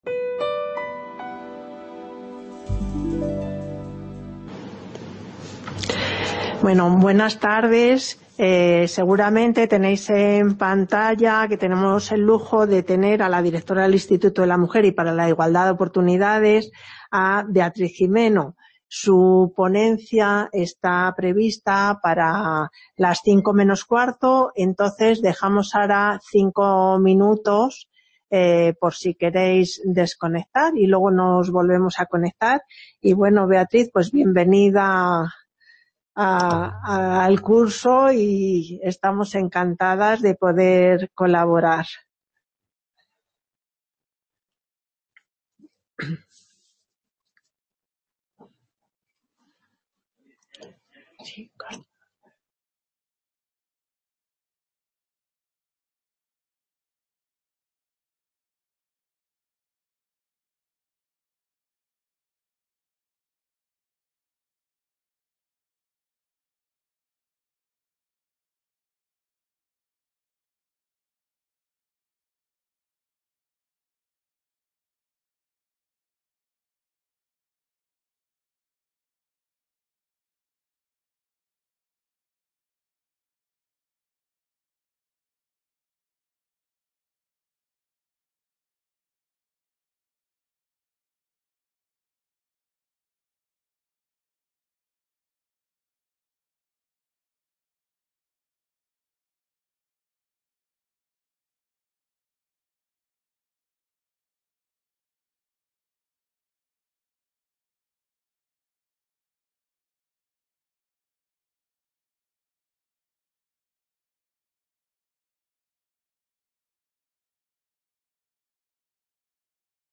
Conferencia Inaugural: El feminismo después de la…
Conferencia Inaugural: El feminismo después de la pandemia, por Beatriz Gimeno Reinoso